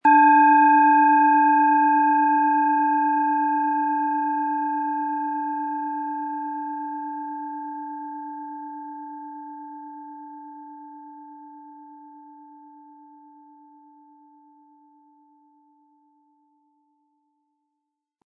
Planetenton 1
Der passende Schlegel ist umsonst dabei, er lässt die Schale voll und harmonisch tönen.
GewichtCa. 171 gr
MaterialBronze